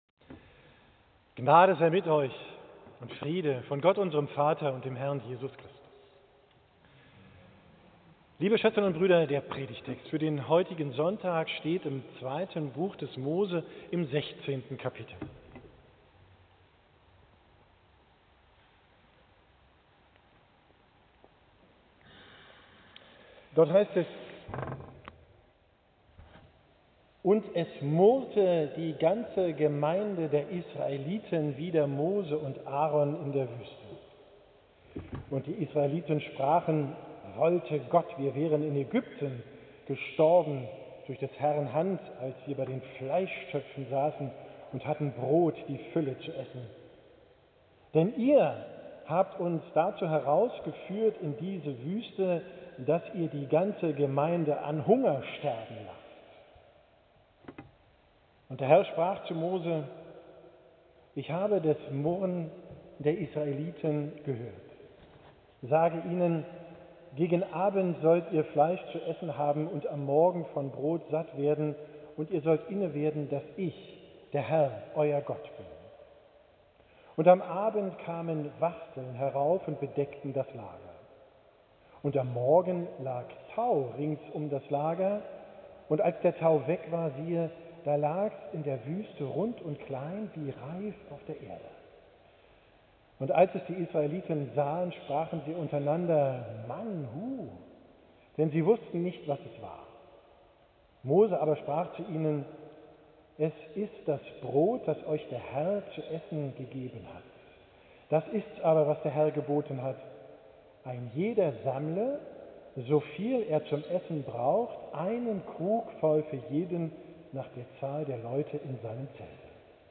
Predigt vom 7.